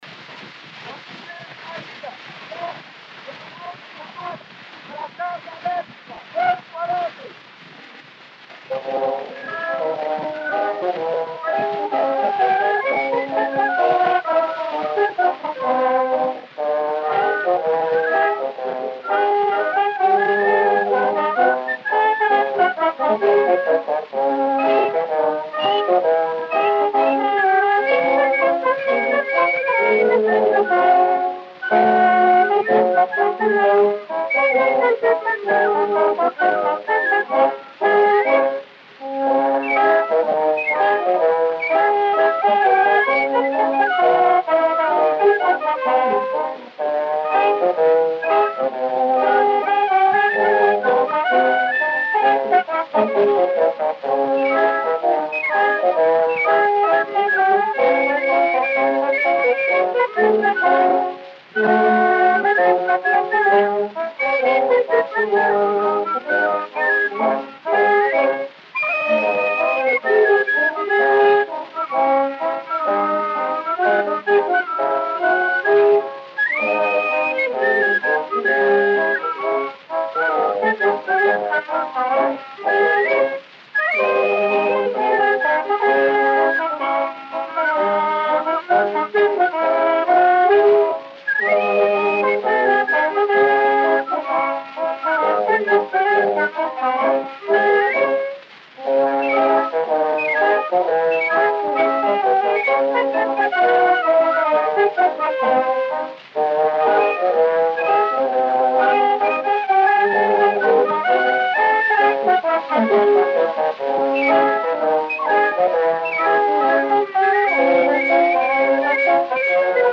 O gênero musical foi descrito como "Schottisch".